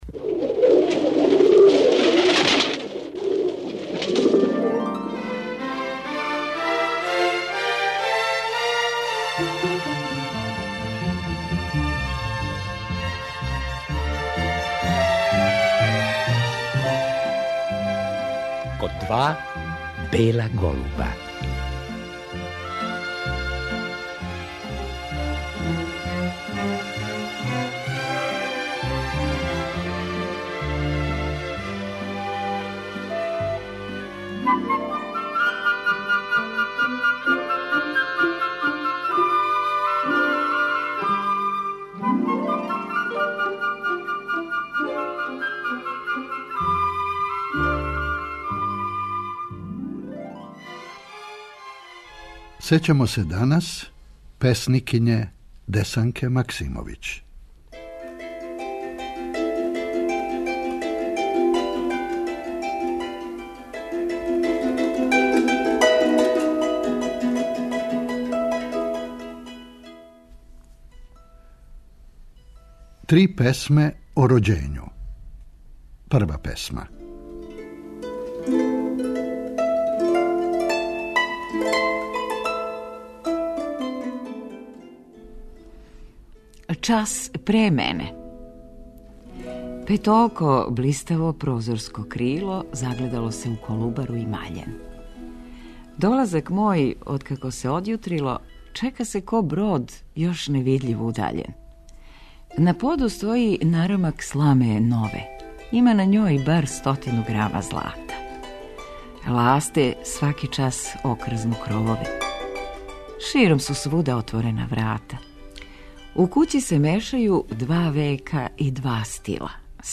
Поводом годишњице рођења Десанке Максимовић, 16. мај 1898. године, говорићемо о певању и мишљењу песникиње. Чућемо снимак са вечери одржане у београдској Скадарлији 1987. године.